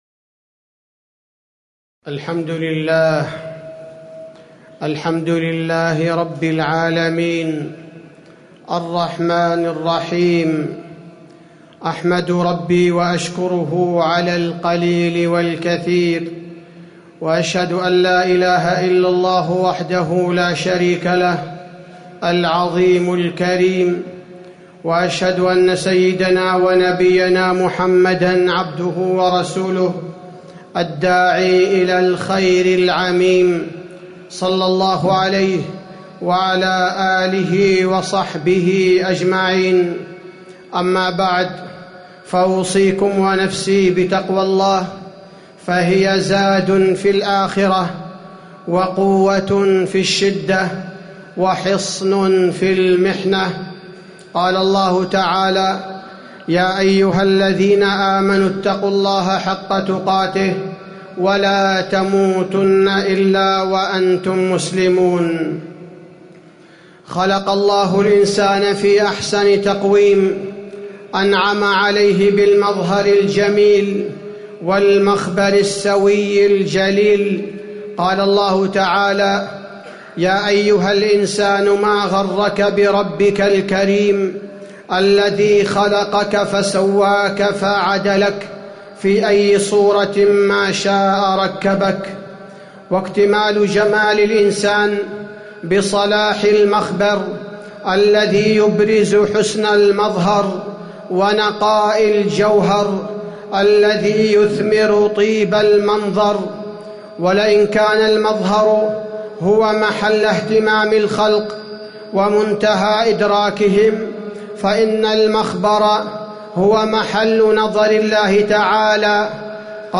تاريخ النشر ٣ ذو الحجة ١٤٣٨ هـ المكان: المسجد النبوي الشيخ: فضيلة الشيخ عبدالباري الثبيتي فضيلة الشيخ عبدالباري الثبيتي صلاح المخبر صلاح للمظهر